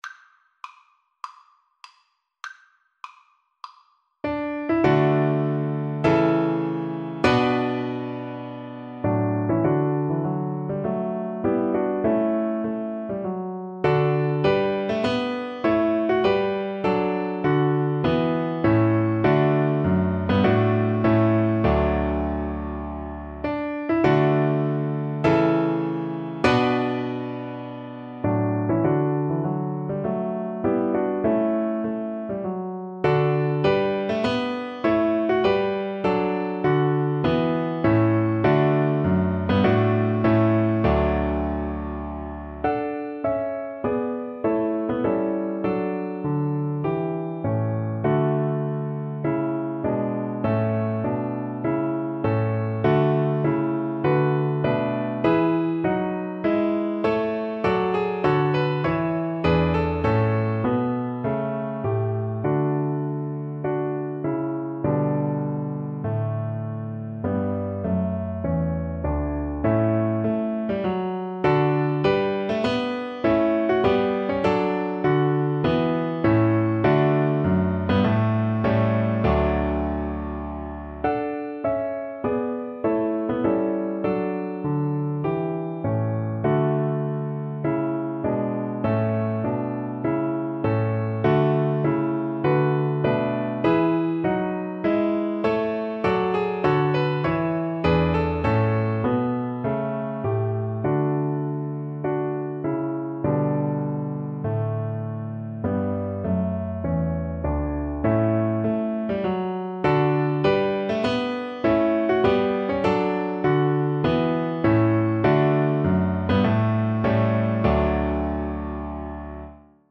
4/4 (View more 4/4 Music)
Classical (View more Classical Violin Music)